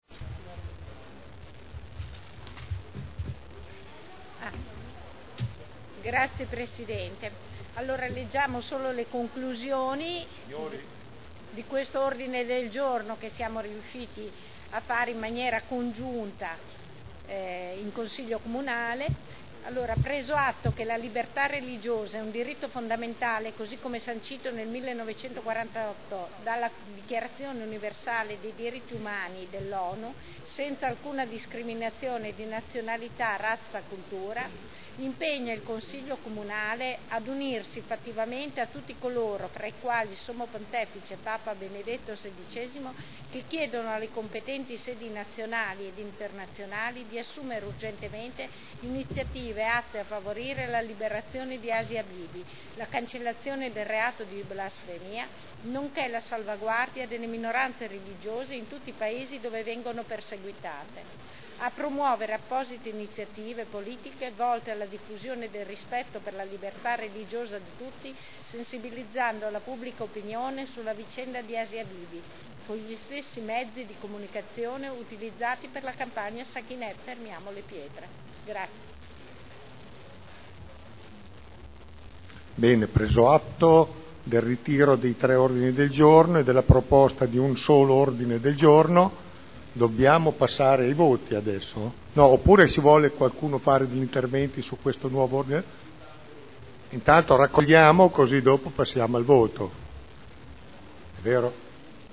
Luigia Santoro — Sito Audio Consiglio Comunale
Seduta del 3/02/2011. presentazione di un unico ordine del giorno sulla difesa della libertà religiosa nella vicenda Asia Bibi